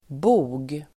Uttal: [bo:g]